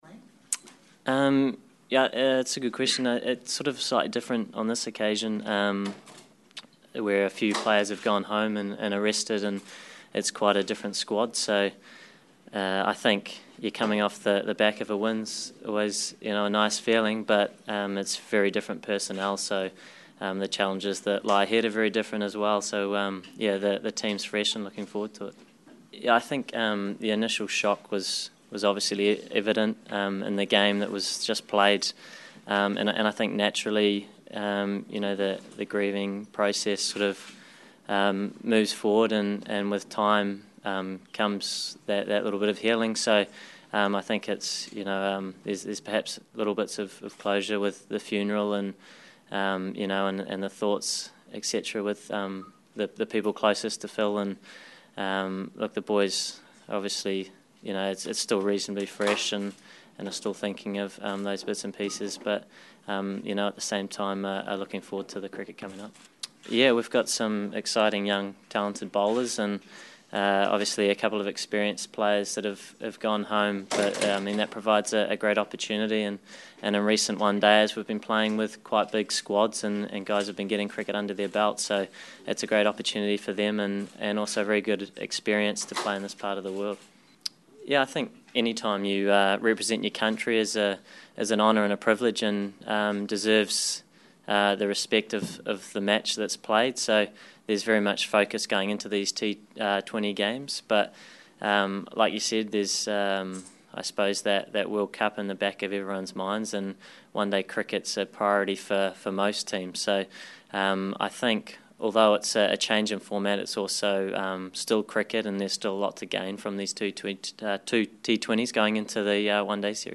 Kane Williamson, the New Zealand Twenty20 captain, media conference, 3 December